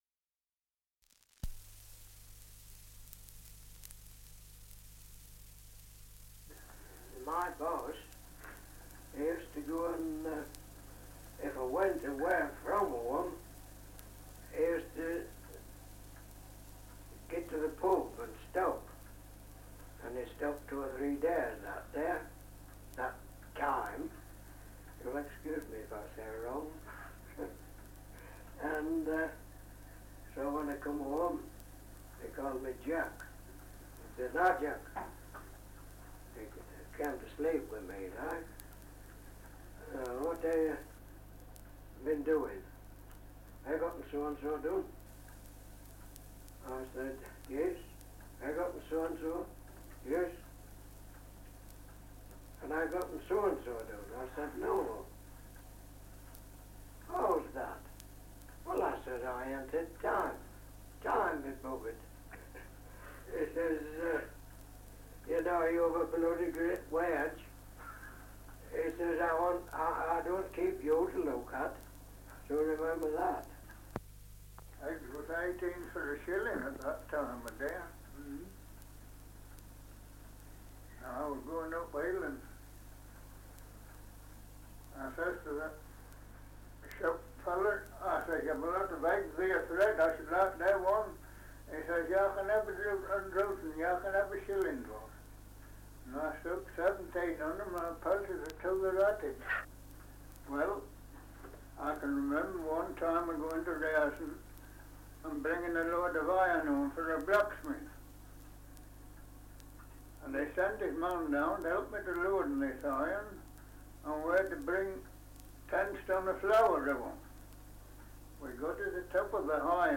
Survey of English Dialects recording in Tealby, Lincolnshire
78 r.p.m., cellulose nitrate on aluminium